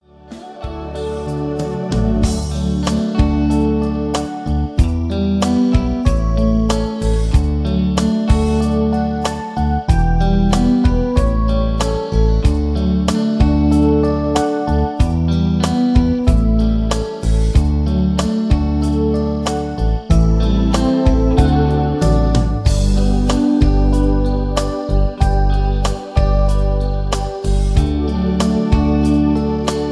Backing Tracks for Professional Singers.